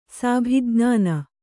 ♪ sābhijñāna